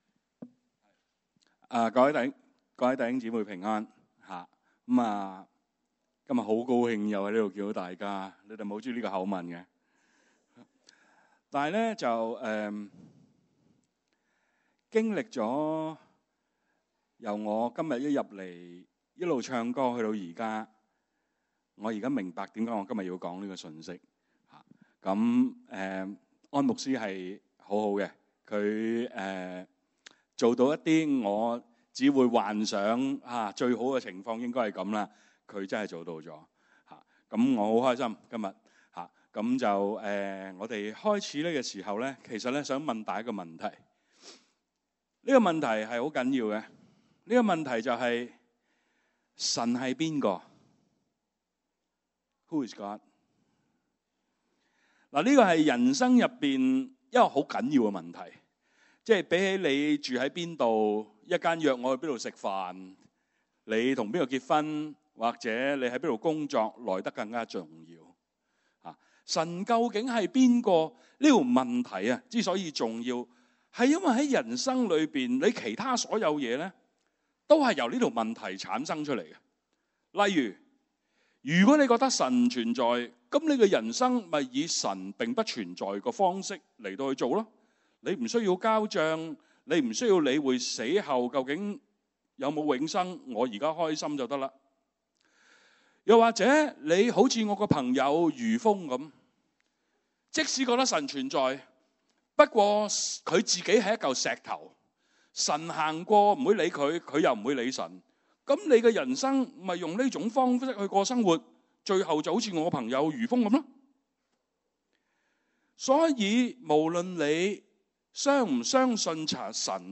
Messages (Chinese) | Home Church